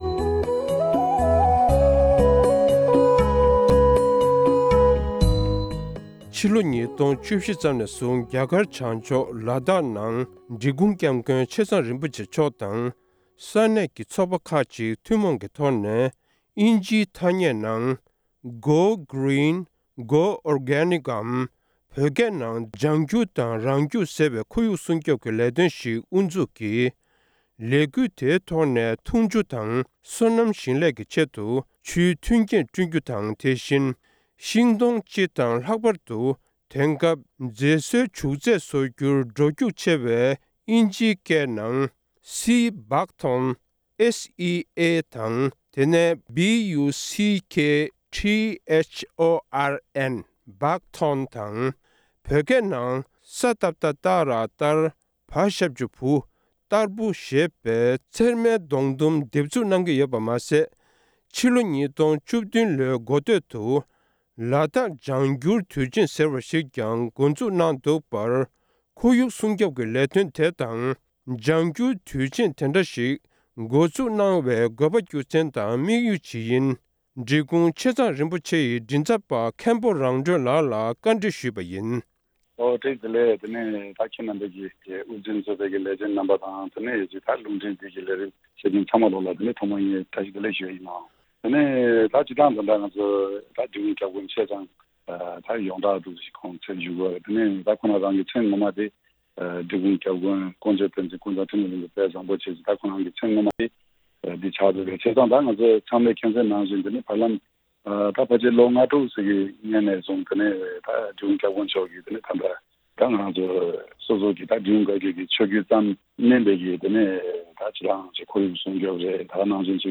སྒྲ་ལྡན་གསར་འགྱུར།
གནས་འདྲི་ཞུས་པ་ཞིག